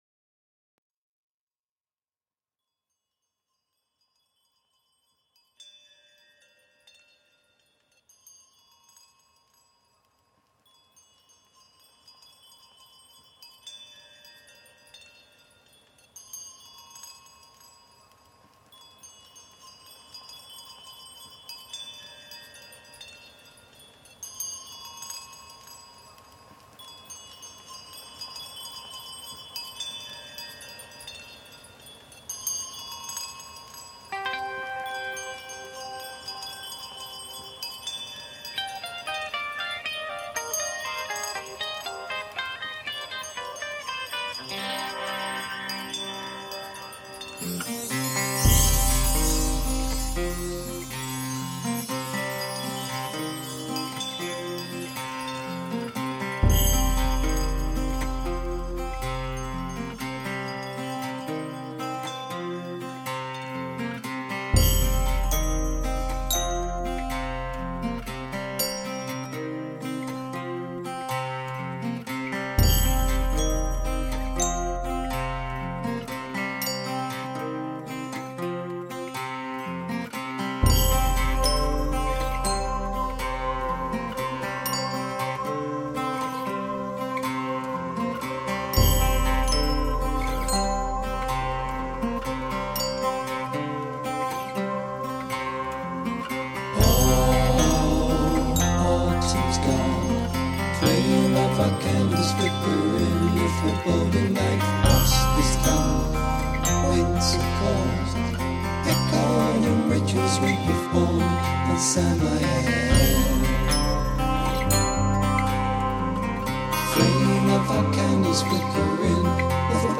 Reindeer bells in Mongolia reimagined